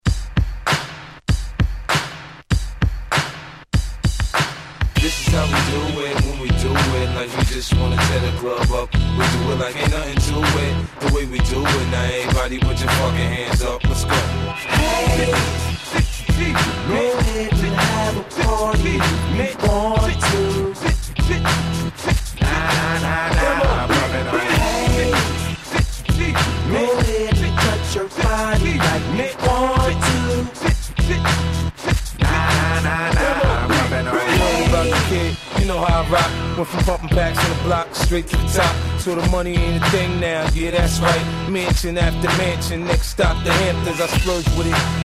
超絶Club Hit 00's Hip Hop !!!!!
とにかくここ日本のClubでもPlayされまくった、使い易いし盛り上がる言うこと無しの1枚です。